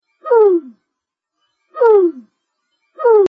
NZ Pigeon
Kererū Call
kereru.mp3